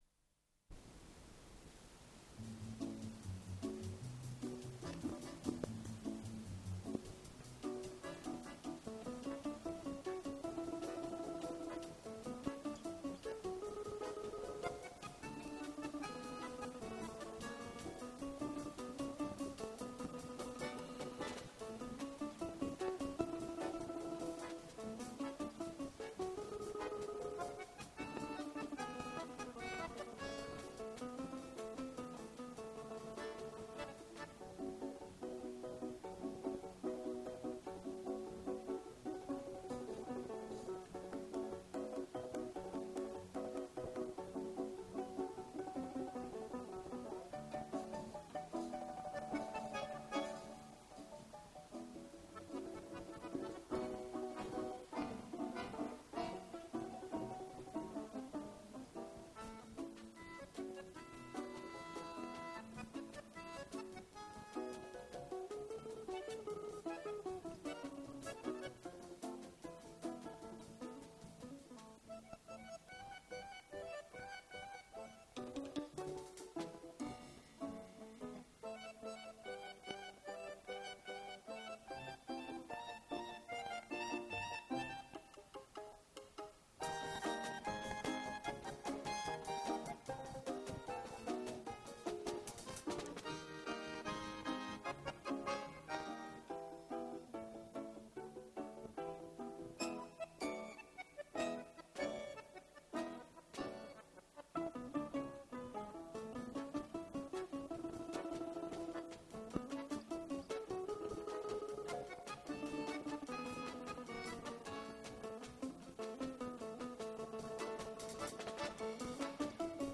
русский  инструментальный ансамбль
балалайка
баян
ударные.
Дубль моно